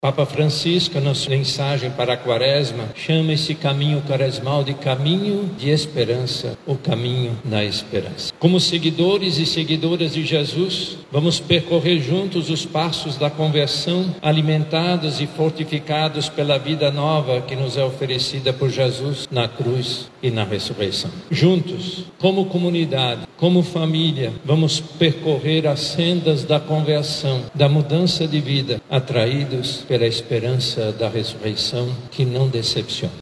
Em Manaus, a cerimônia aconteceu na Catedral Metropolitana, presidida pelo Cardeal Leonardo Steiner, e contou com a presença de centenas de fiéis.